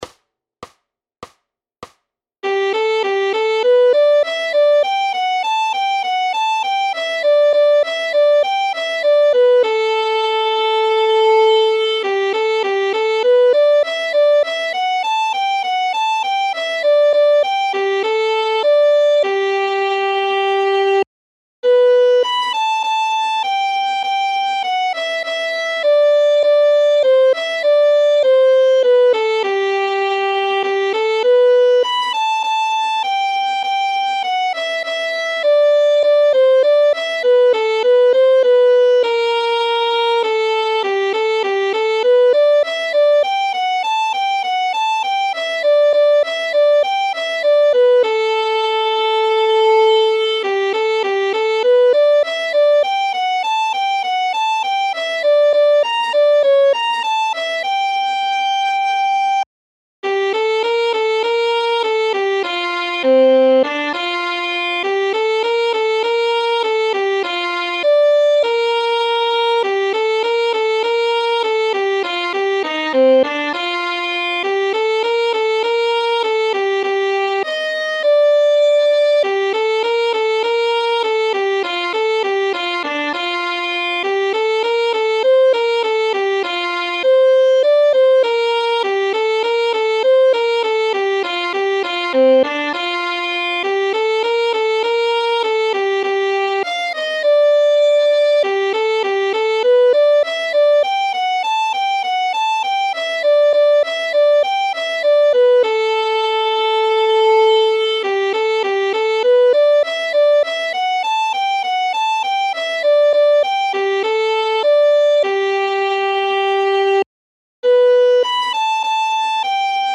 Noty na housle.
Formát Houslové album
Hudební žánr Klasický